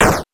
explosion_9.wav